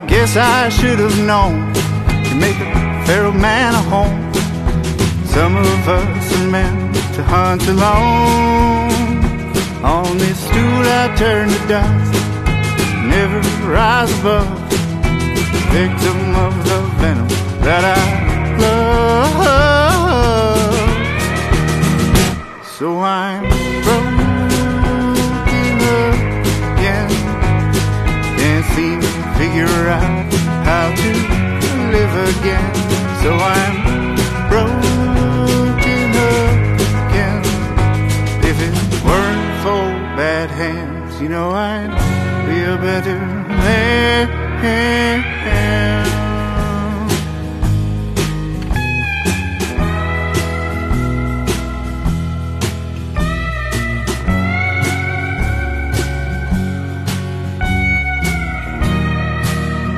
Lead Guitar
Bass
Drums